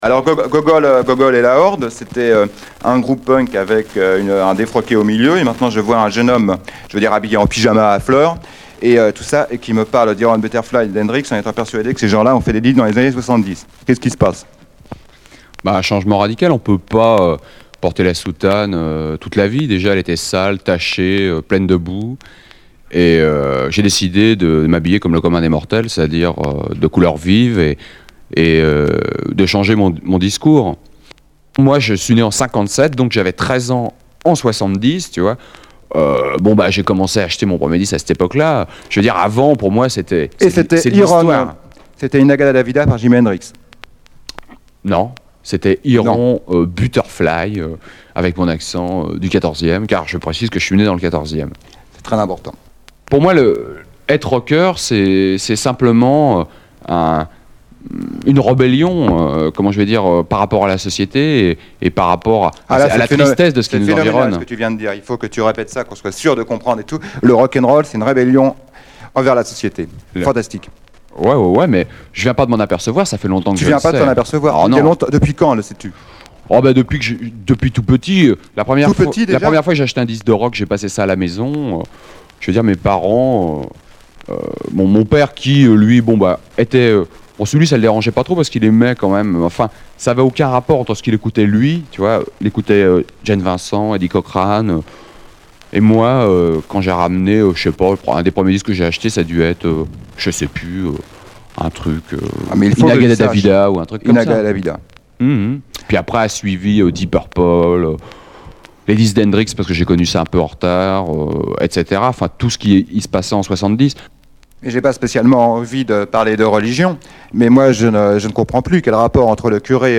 45t promo avec interview